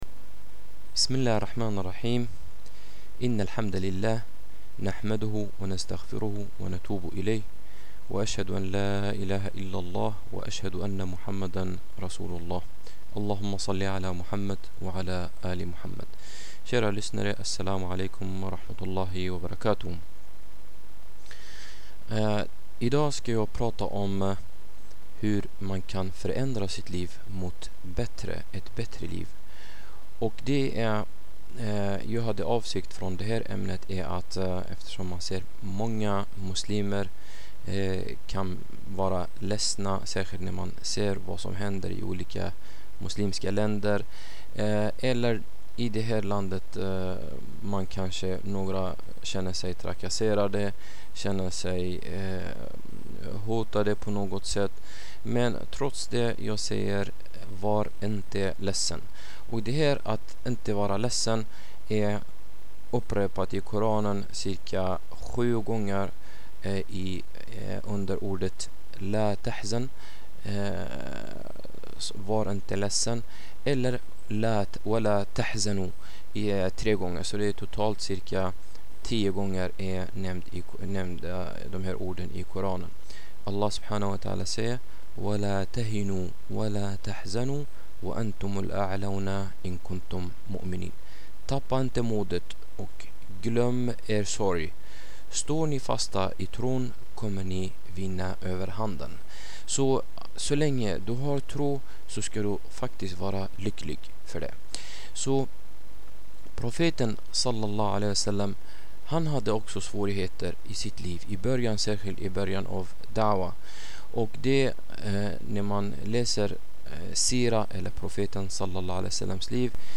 Ett föredrag om hur man kan förändra sitt liv mot ett bättre